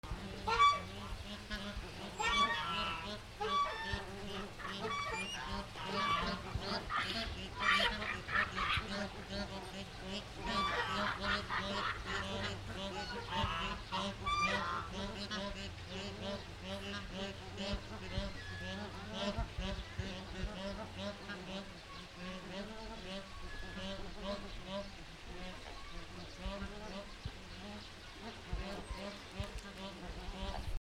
水鳥
MKH416